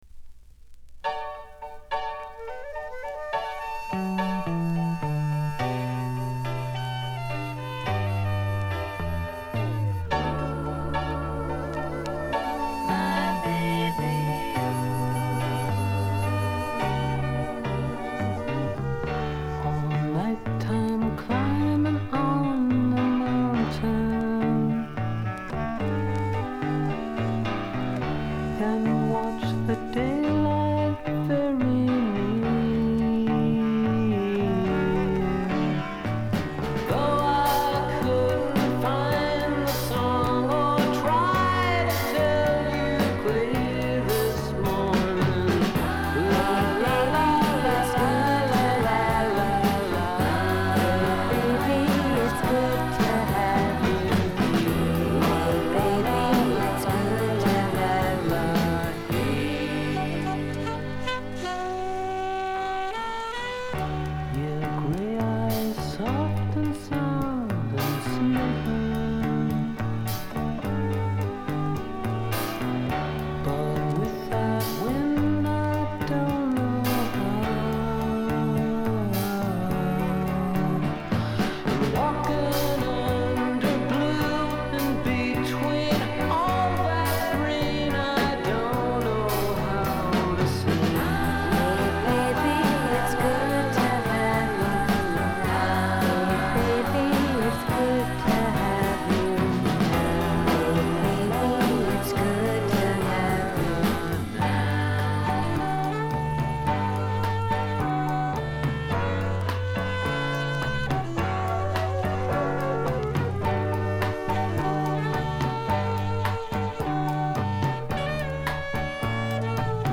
sophisticated 60s pop with woodwinds galore.